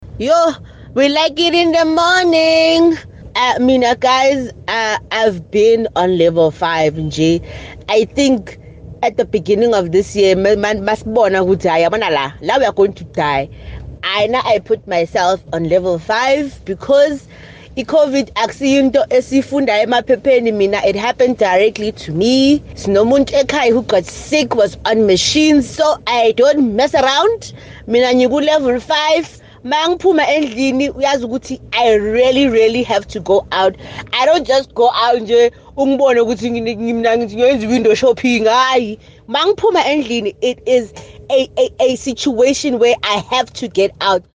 There’s mixed views on what will be announced and a few listeners shared their thoughts: